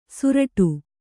♪ suraṭu